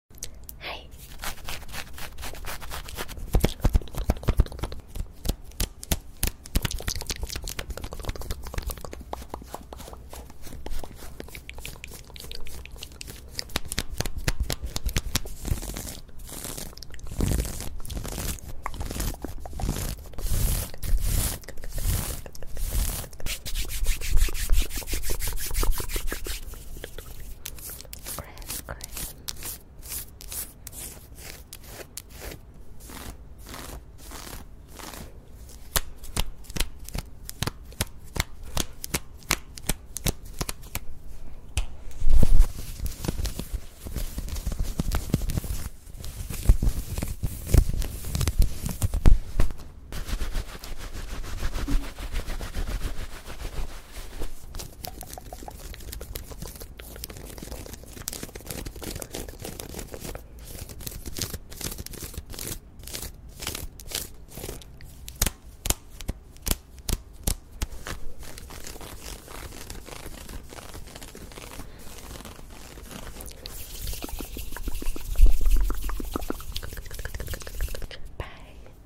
ASMR fast and aggressive scrathing